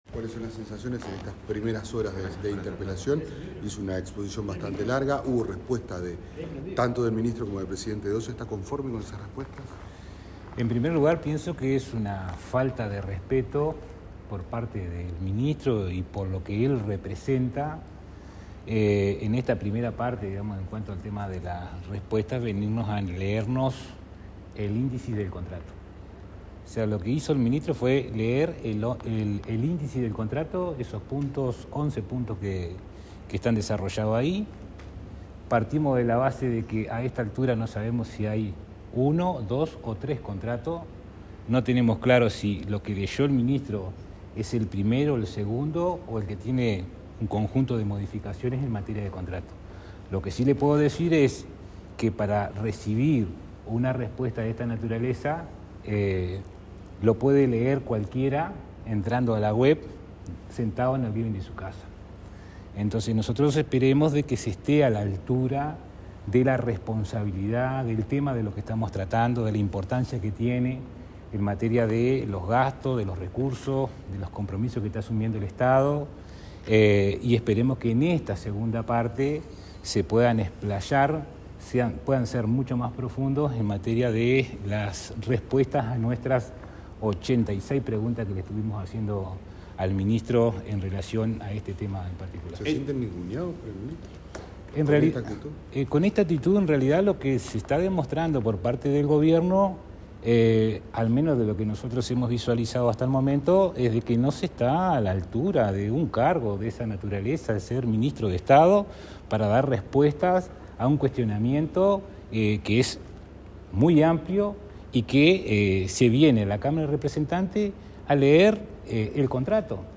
Consultado por la prensa, el diputado Felipe Carballo sostuvo que al momento, “lo que hizo el ministro fue leer el índice del contrato”y que a esta altura “no sabemos si hay uno o dos o tres contratos, No tenemos claro si lo que leyó el ministro es el primero, el segundo o el que tiene un conjunto de modificaciones en materia de contrato”.
Carballo_dec_prensa-2.mp3